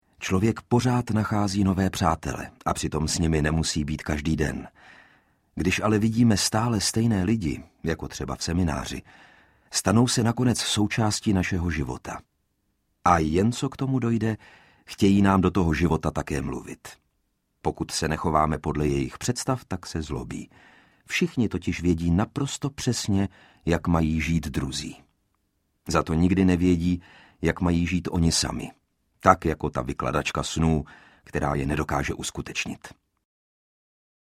Alchymista audiokniha
Ukázka z knihy
• InterpretLukáš Hlavica